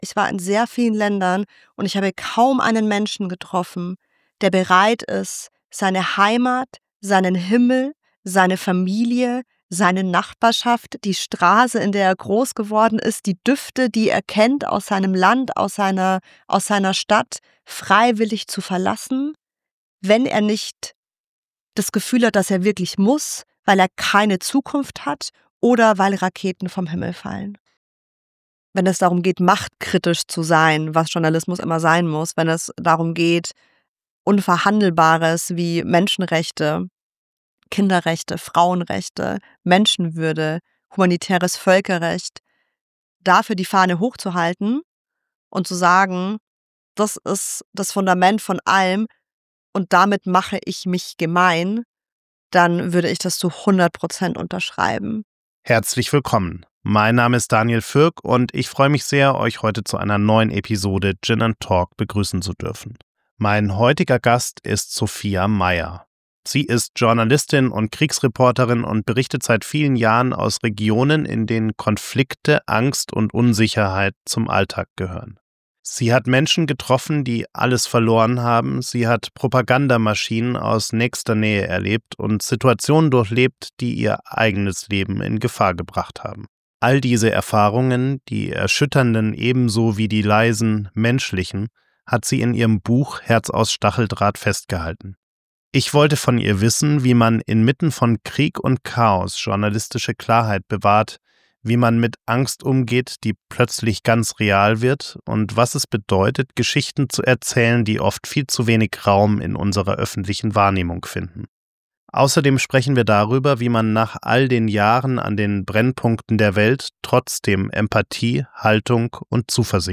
Ein Gespräch über Angst, Verantwortung und die fragile Grundlage unseres Vertrauens.